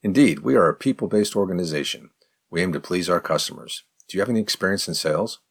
Spoken fast:
03_advanced_question_fast.mp3